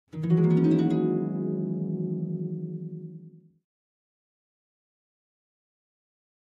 Harp, Medium Strings Short Ascending Gliss, Type 3